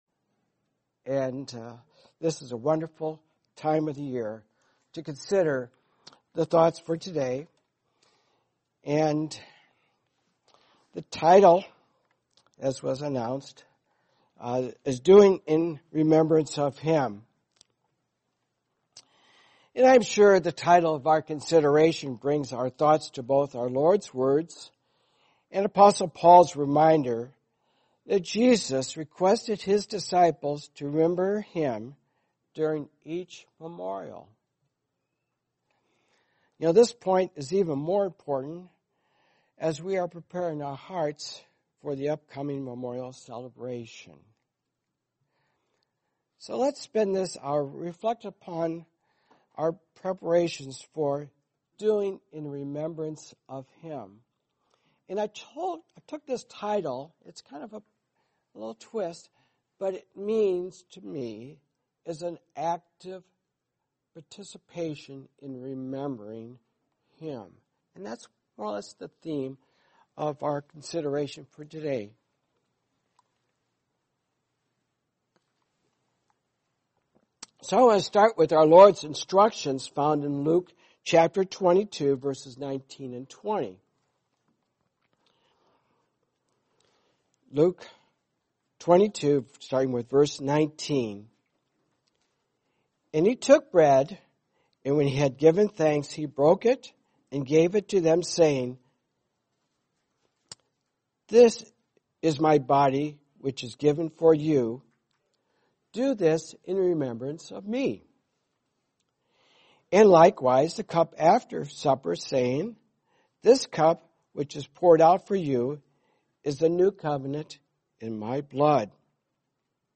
Series: 2025 Wilmington Convention